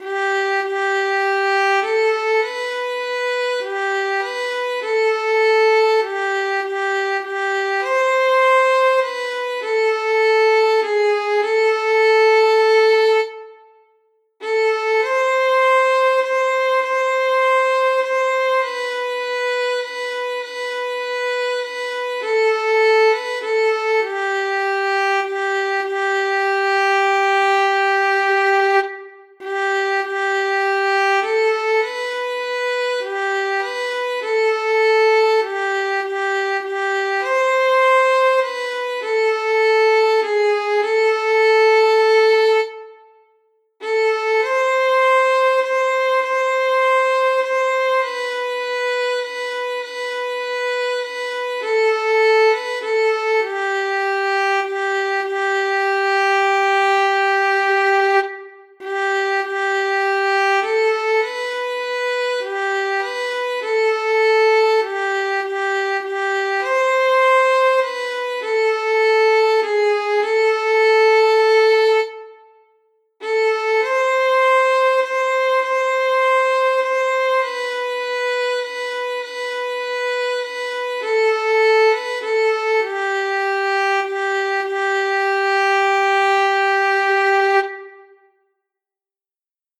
41_isabella_31937_st1_7_13_ladysfall_fiddle.mp3 (3.43 MB)
Audio fiddle of transcribed recording of stanzas 1, 7, and 13 of “The Lady Isabella’s Tragedy”; sung to “The Ladies Fall”